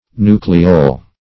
Nucleole \Nu"cle*ole\, n. [See Nucleolus.]